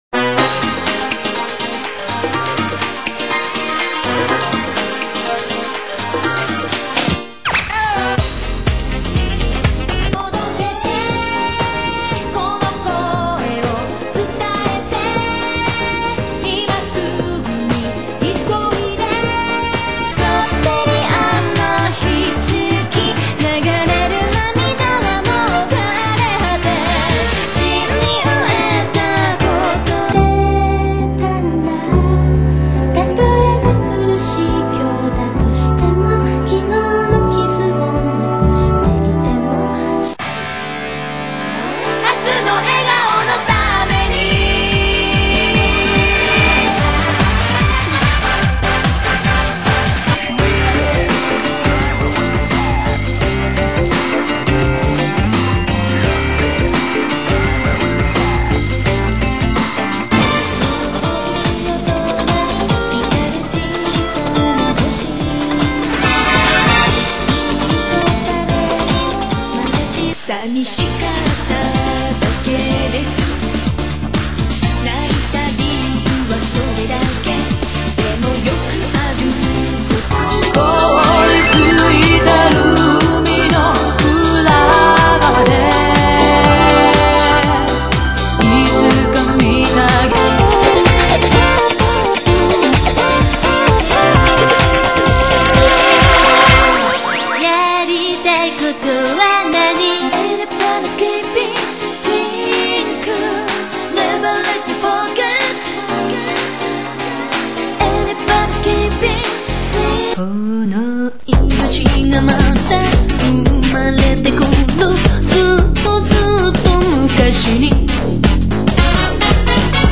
Favorite Anime Songs